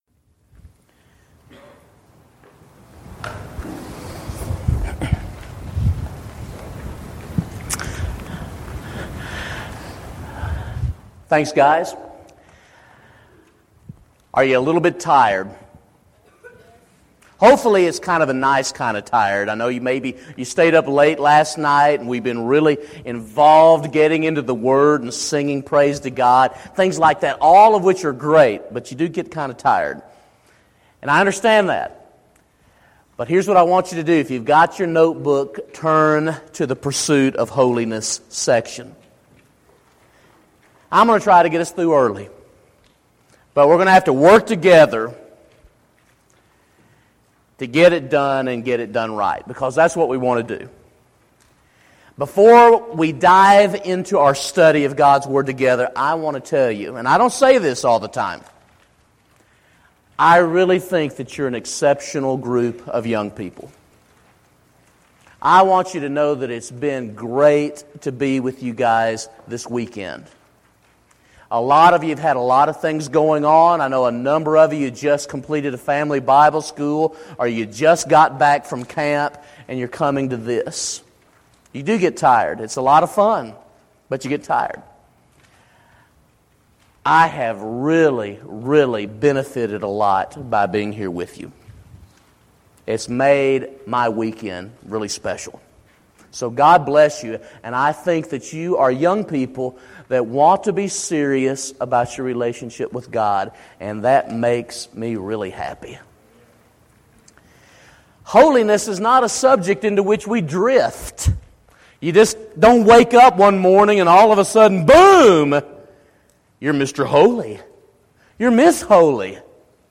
Keynote 3
Event: Discipleship University 2012